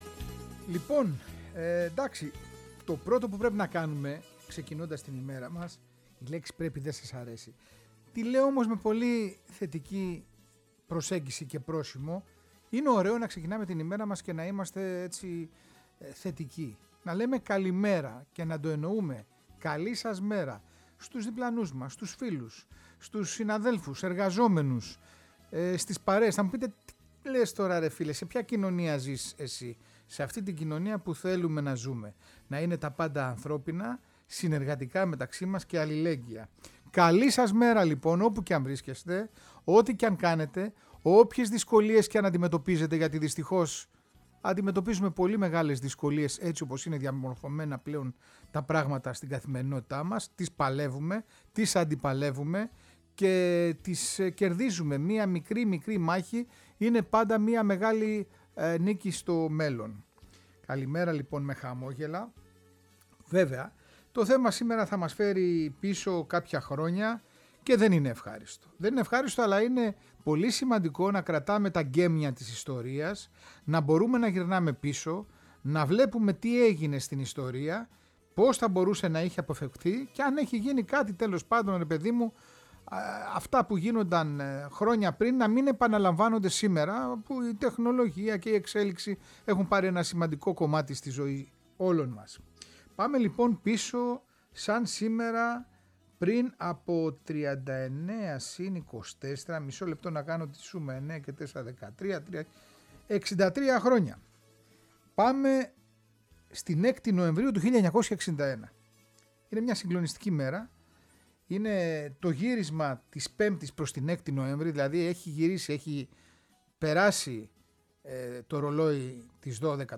εμπλουτισμένη με τραγούδια της εποχής…